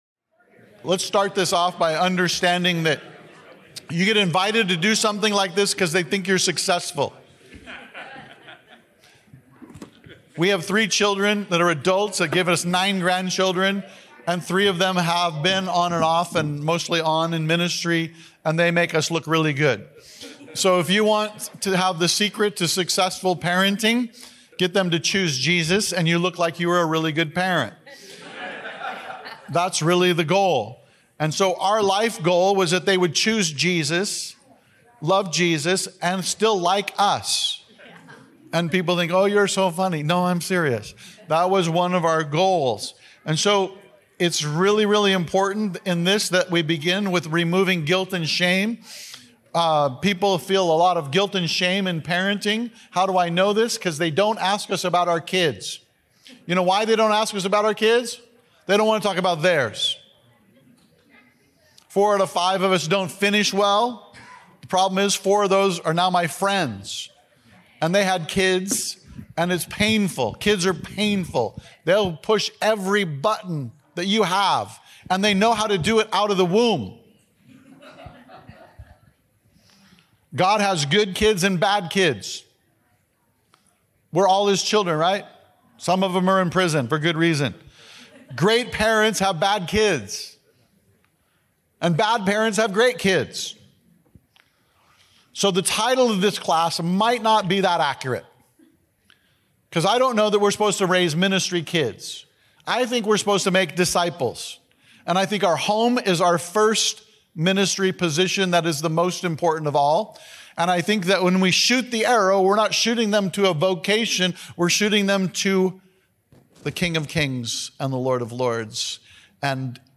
This workshop from Foursquare Connection 2023 explores the importance of home as our first ministry priority to create environments that disciple our children to follow Jesus.